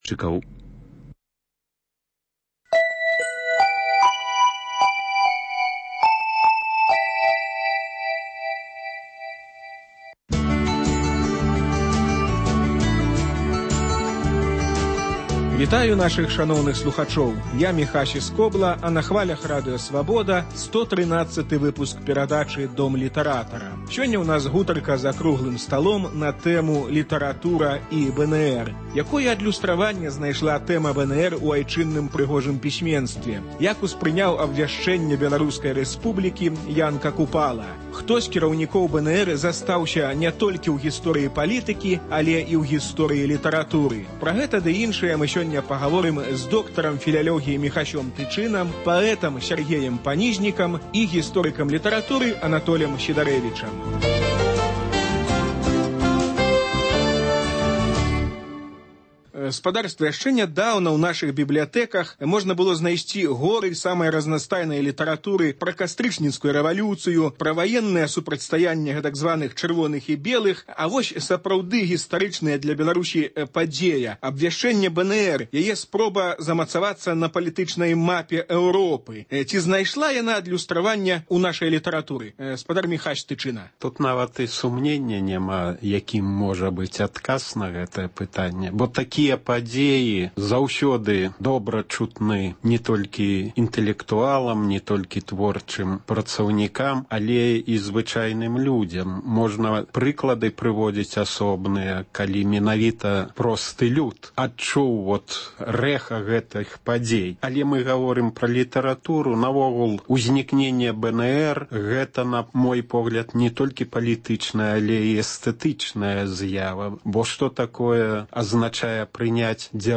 Круглы стол на тэму "Літаратура і БНР"